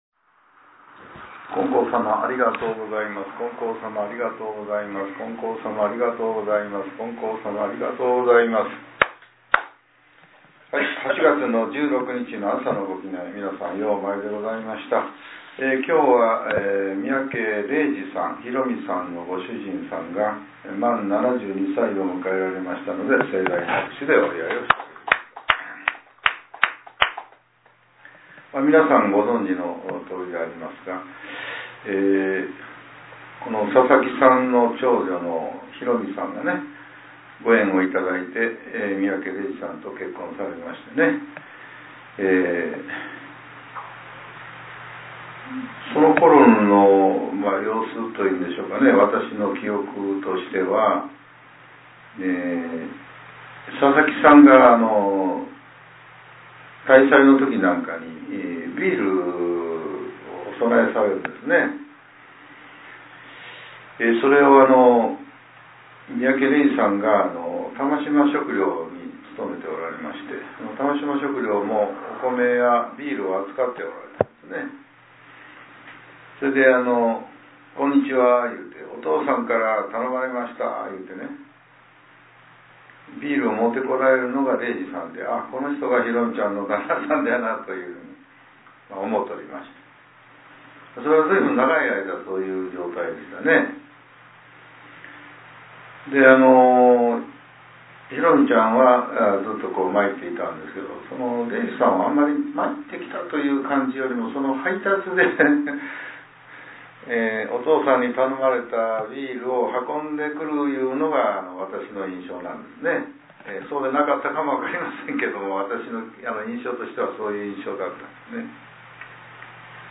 令和７年８月１６日（朝）のお話が、音声ブログとして更新させれています。